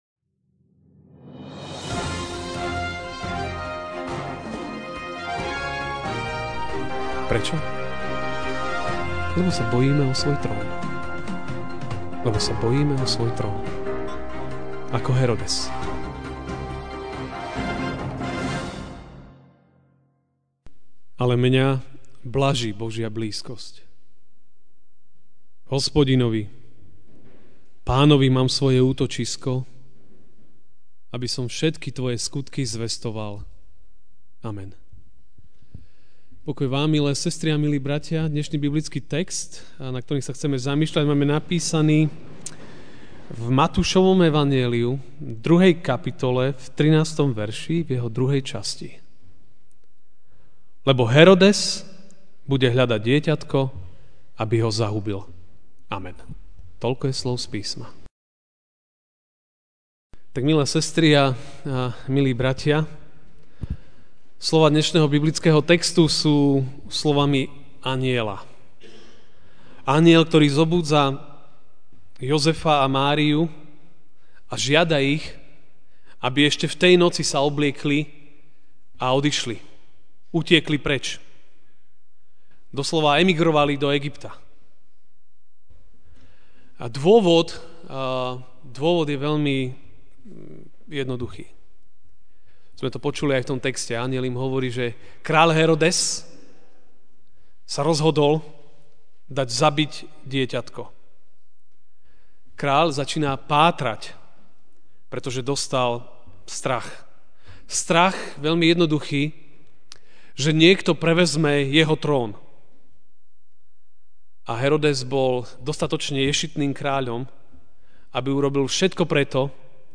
jan 06, 2018 (Ne)chcený kráľ MP3 SUBSCRIBE on iTunes(Podcast) Notes Sermons in this Series Ranná kázeň: (Ne)chcený kráľ (Mt. 2, 13b) ... Herodes bude totiž hľadať dieťatko, aby ho zmárnil.